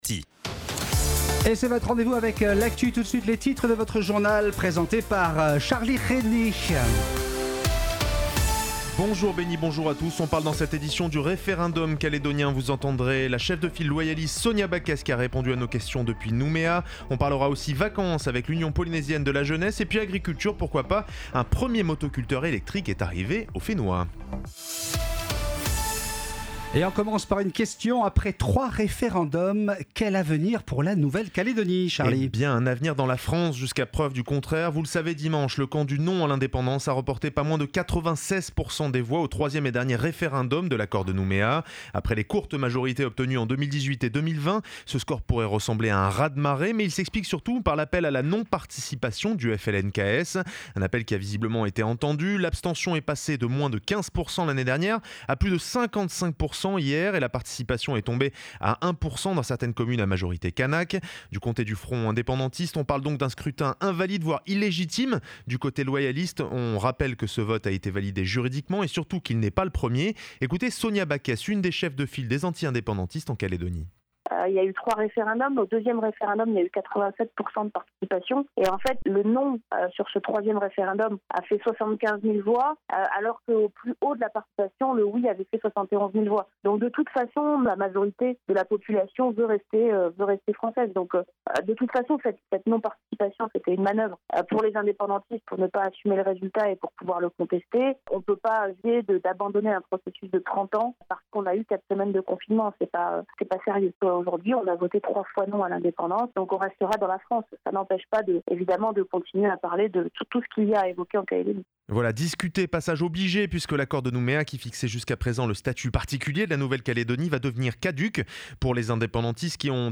Journal de 12h, le 13/12/21